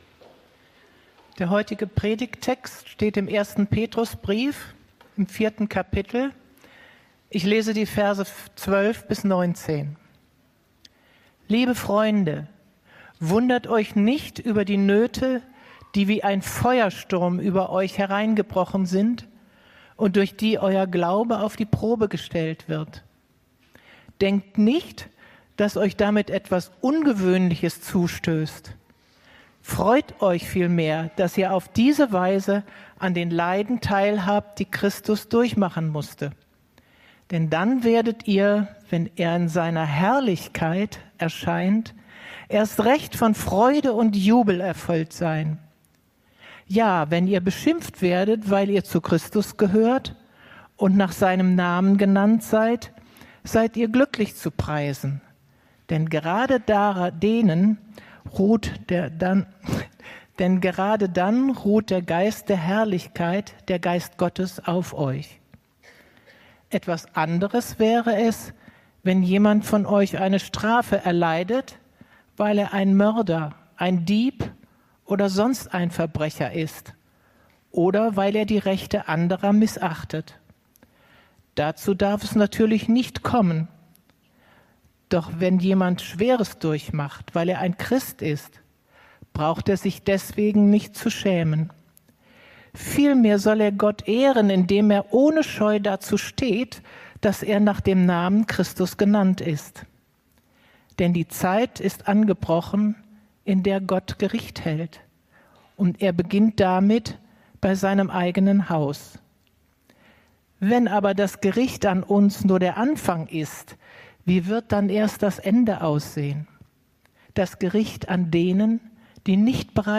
Predigten – Er-lebt.